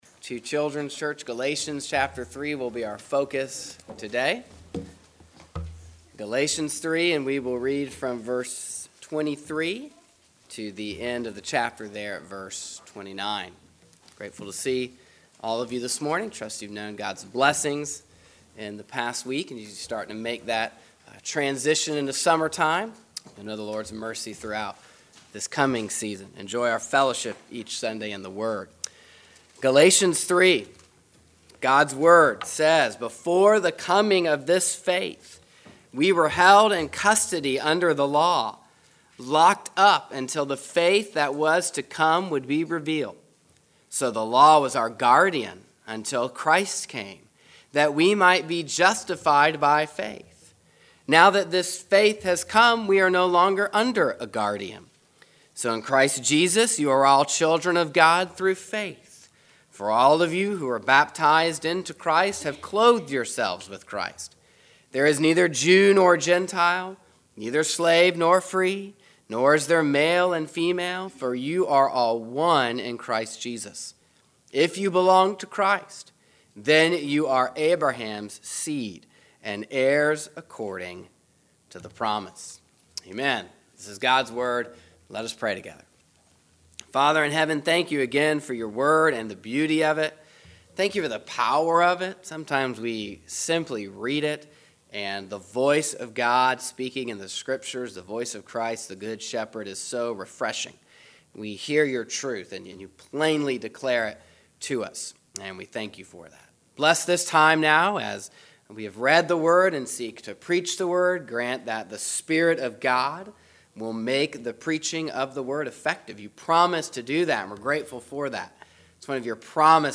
This sermon show us that either we are trying to build our own identity or we find our identity in Christ. When we find that we cannot keep God's commandments, we see that we need a savior, just like the Jews. When we come to Christ by faith we become the children of God.